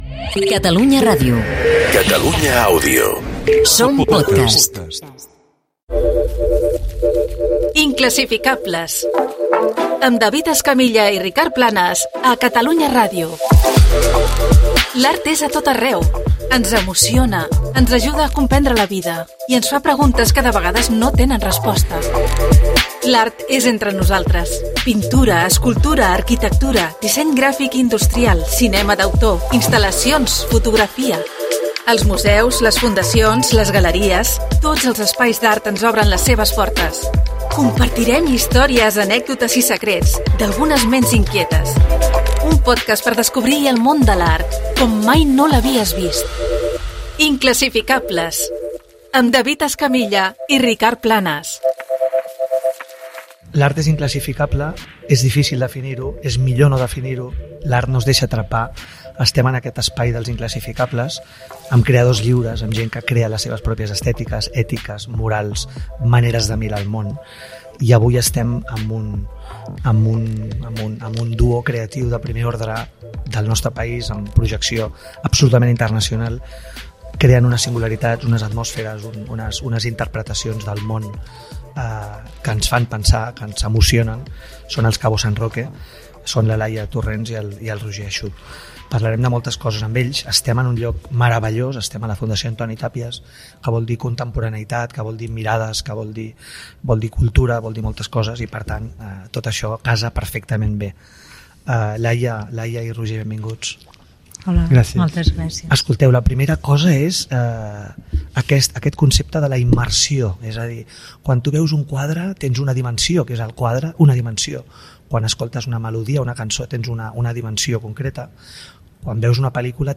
Indicatiu de Catalunya Ràdio-Catalunya Àudio, careta, presentació i conversa amb Cabosanroque, dos creadors sonors, a la Fundació Antoni Tàpies